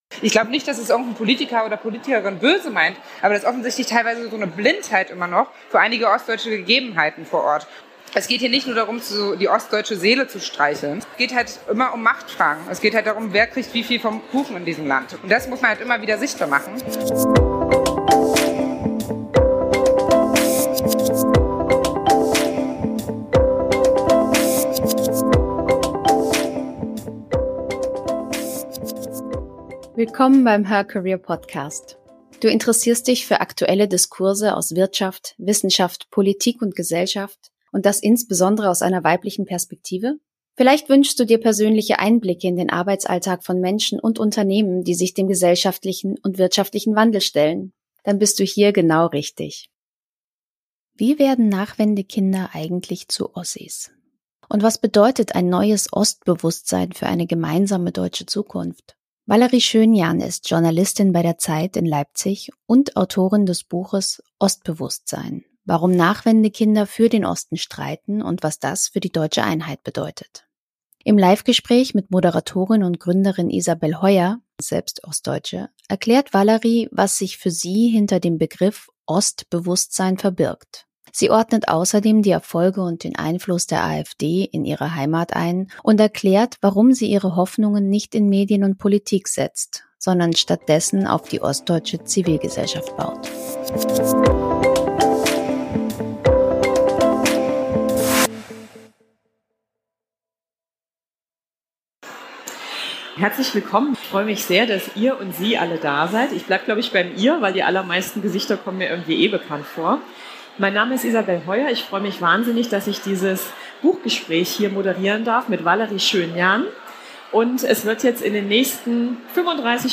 Live-Gespräch